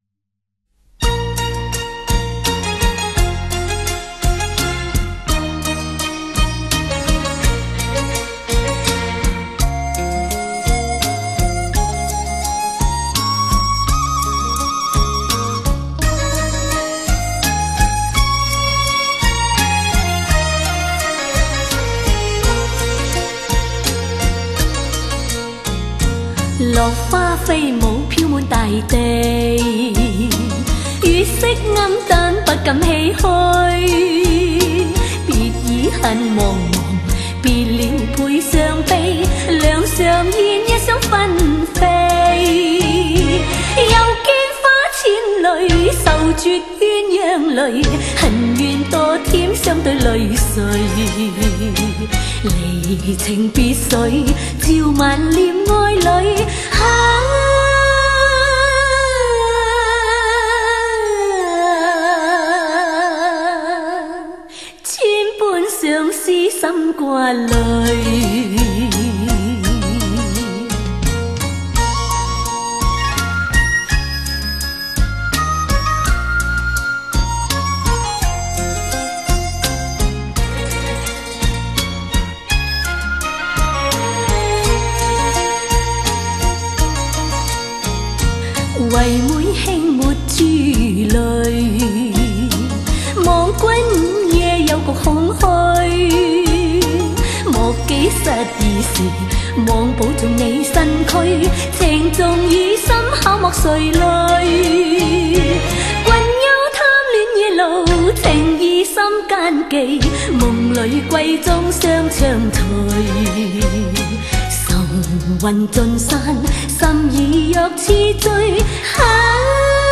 广东粤语小调